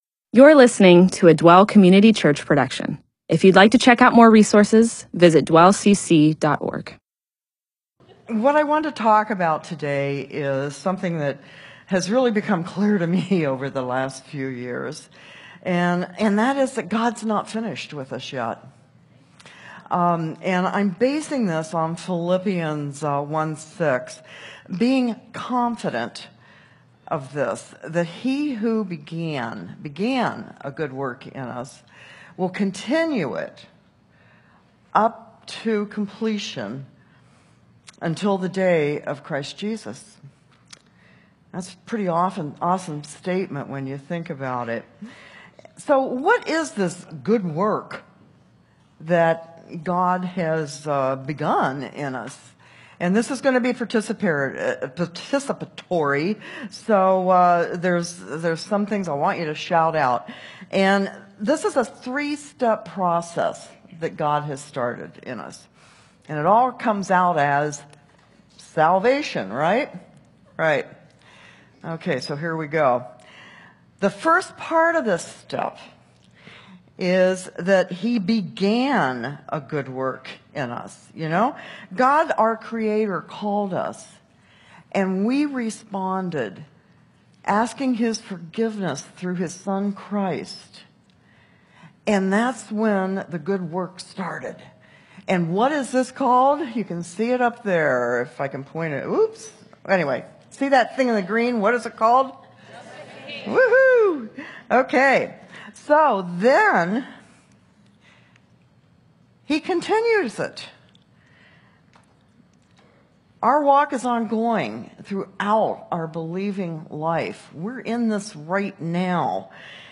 MP4/M4A audio recording of a Bible teaching/sermon/presentation about Philippians 1:6.